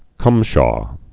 (kŭmshô)